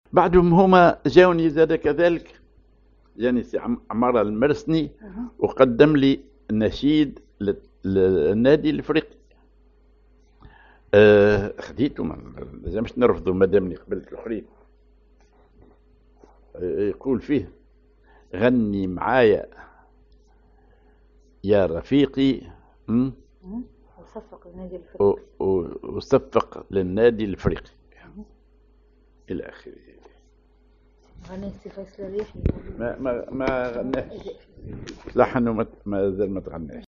genre أغنية